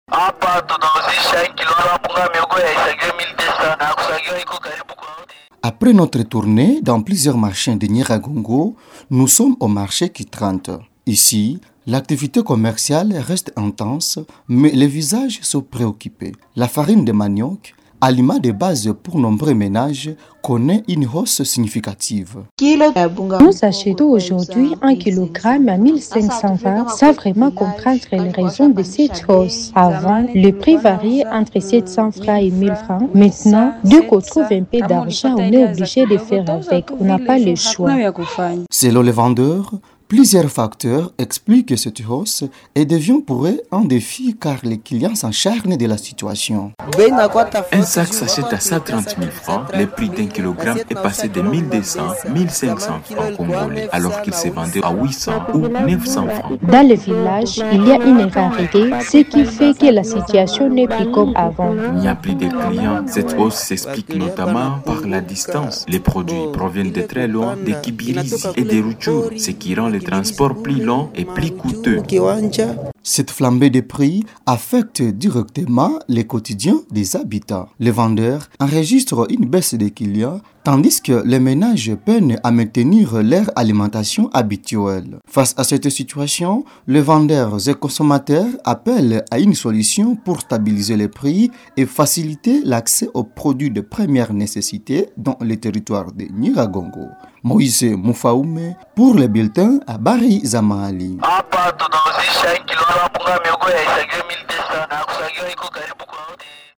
Au marché de Ki 30, l’activité commerciale reste dynamique, mais l’ambiance est marquée par l’inquiétude des vendeurs et des acheteurs.
REPORT.-FR-HAUSSE-DES-PRIX-DE-FARINE.mp3